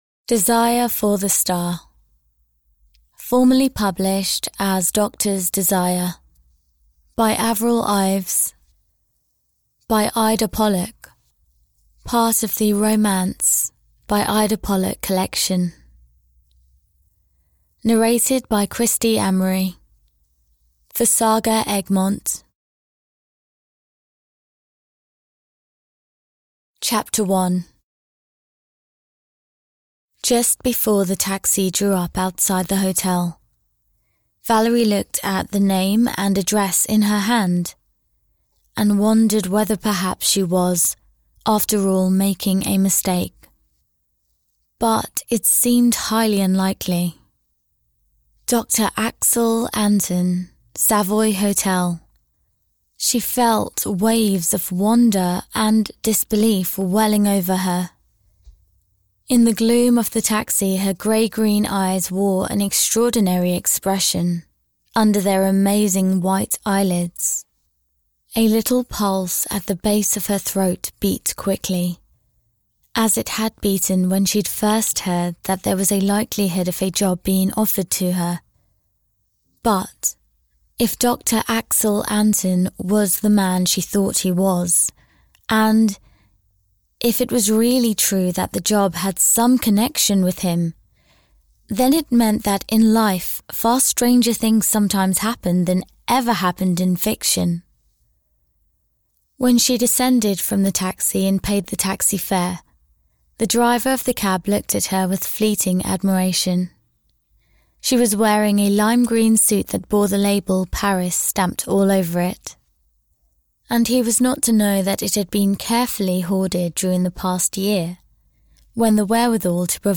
Desire for the Star (EN) audiokniha
Ukázka z knihy